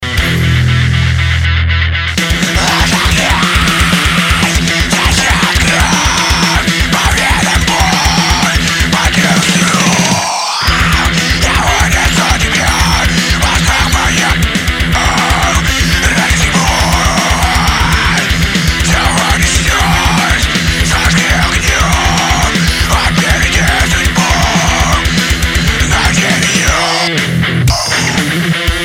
âîò åùå ïðèìåð(äîìà ïèñàë) íåêîòîðûå äóìàþò ÷òî ýòî ó ìåíÿ ñêðèì( íî ýòî âåðõíèé ãðîóë) :idea2: